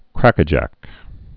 (krăkə-jăk)